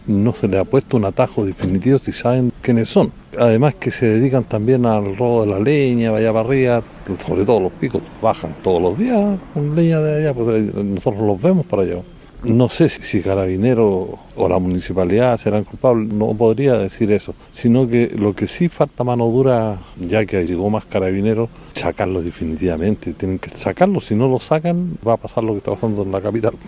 Otro de los habitantes de la comuna aseguró que están todos identificados, pero que hace falta mano dura.